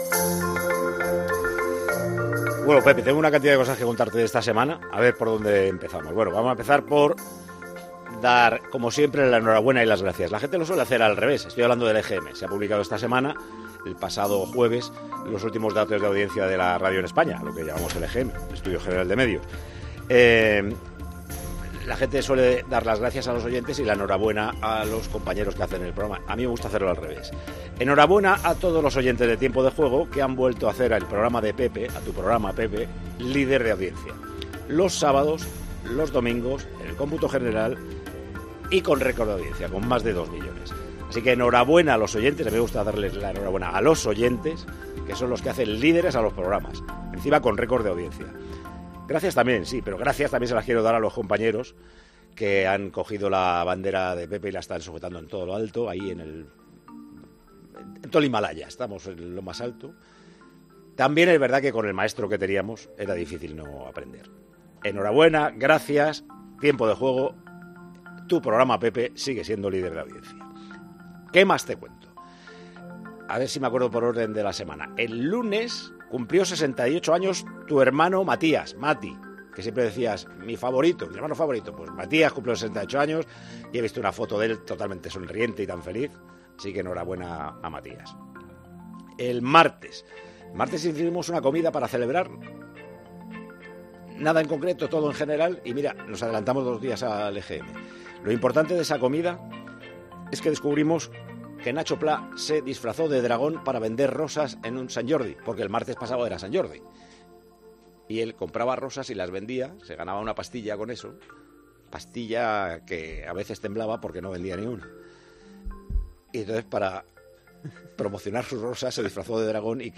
El director y presentador de Tiempo de Juego dedicó unas palabras a Pepe Domingo Castaño en el comienzo del programa de este sábado tras los datos de audiencia del EGM.